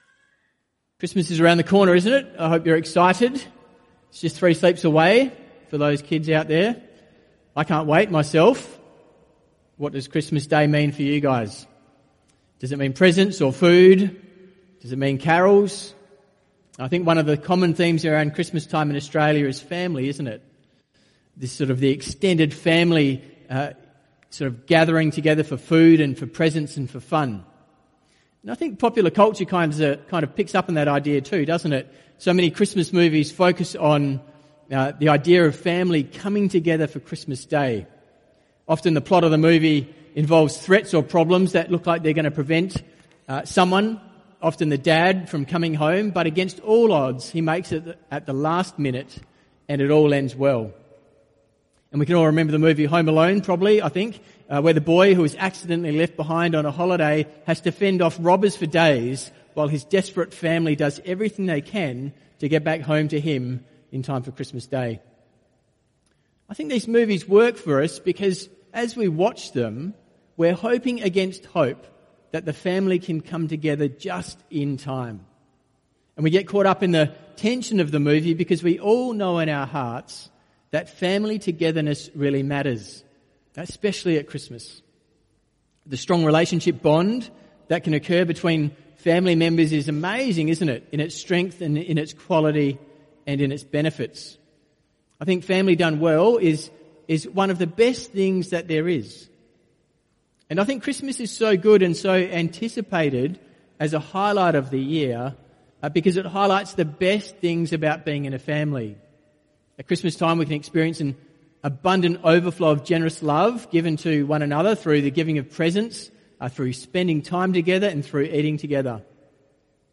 Passage: Matthew 1:18-25 Type: Sermons